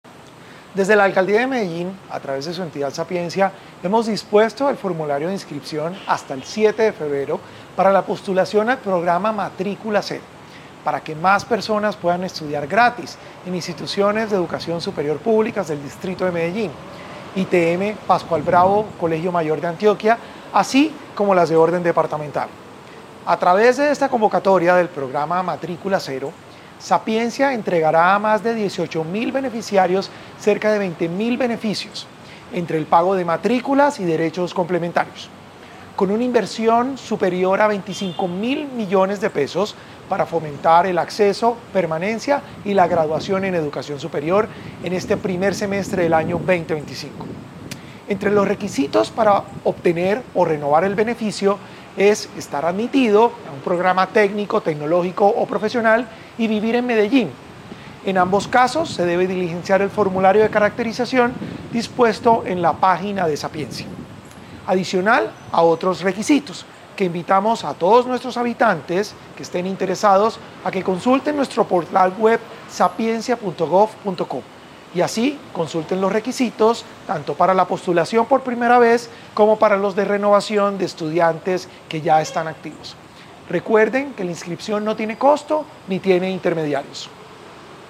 Palabras de Salomón Cruz Zirene, director general de Sapiencia